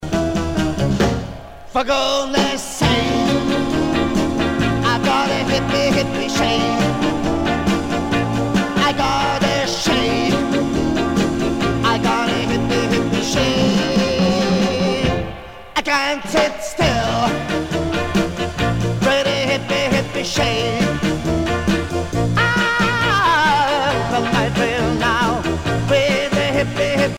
danse : madison
Pièce musicale éditée